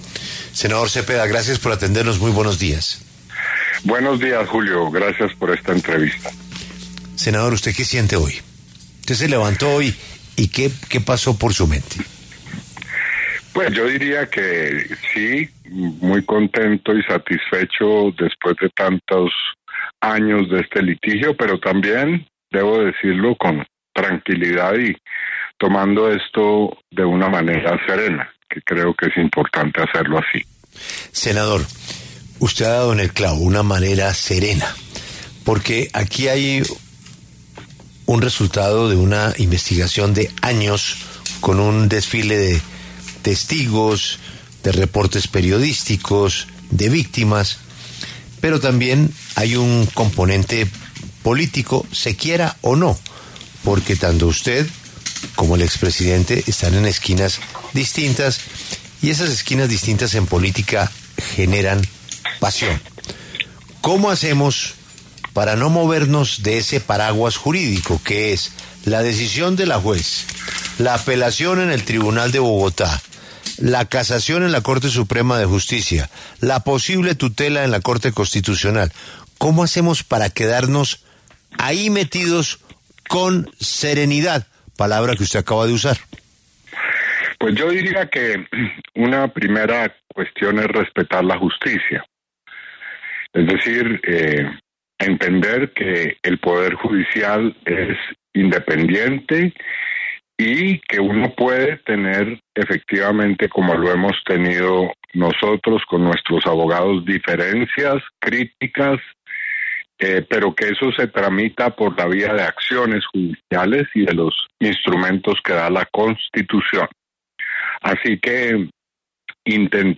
Iván Cepeda, senador y una de las principales figuras del caso de Álvaro Uribe, pasó por los micrófonos de La W.